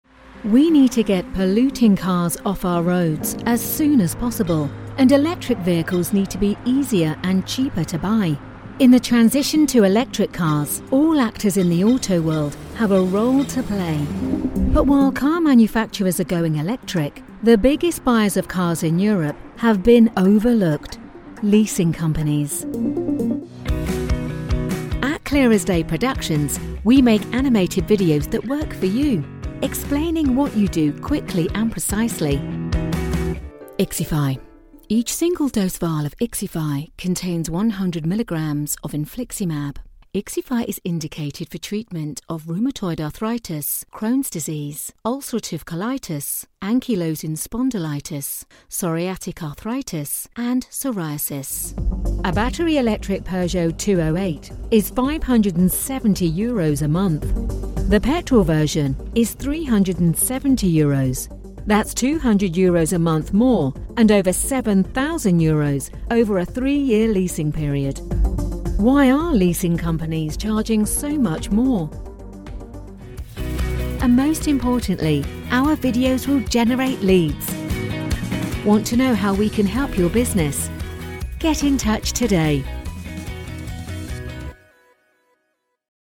Unternehmensvideos
Meine Stimme wird als nahbar, voll, selbstsicher, sanft, selbstbewusst und vertrauenswürdig beschrieben.
Professionelle Gesangskabine mit kabelloser Tastatur und Maus.
Focsurite Scarlett 2i2, D2 Synco-Richtmikrofon und Twisted-Wave-Aufnahmesoftware.
Niedrig